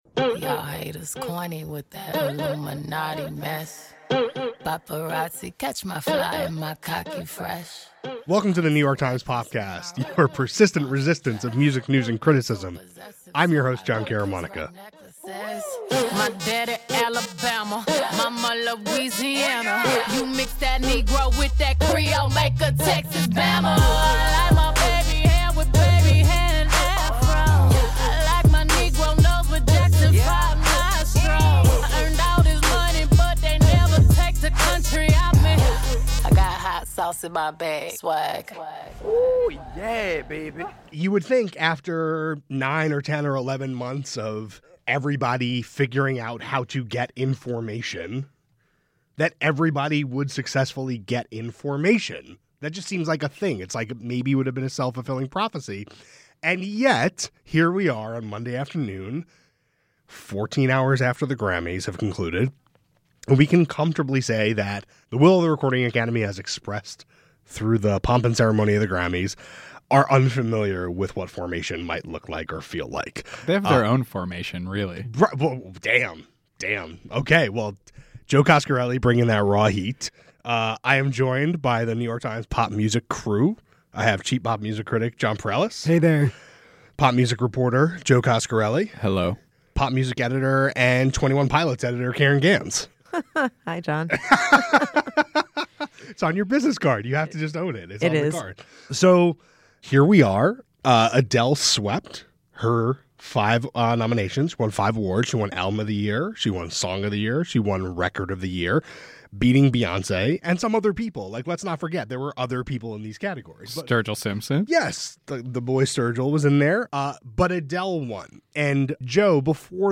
It was an awards show filled with flubs, tear, apologies and protests. The New York Times pop music writers discuss Adele, Beyoncé, Chance the Rapper and more on Popcast.